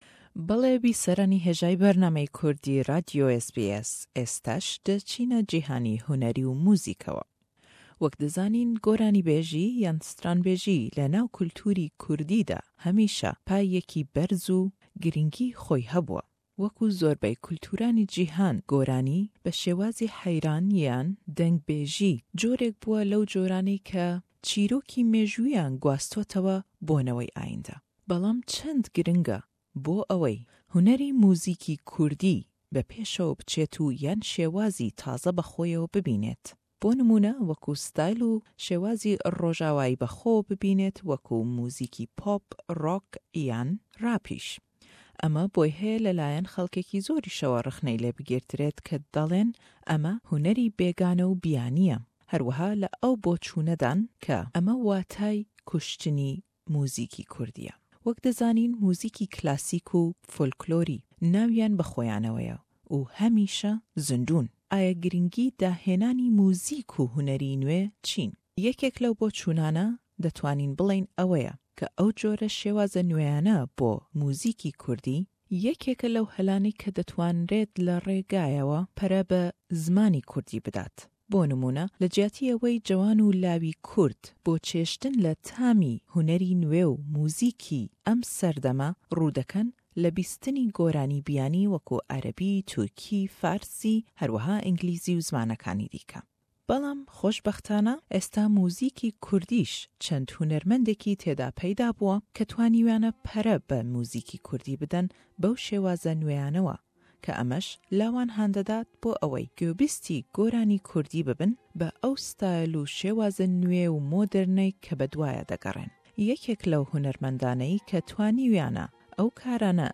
Le em lêdwane da le gell hunermendî Pop-î Kurdî
Ew goraniyaney le gell em lêdwanedan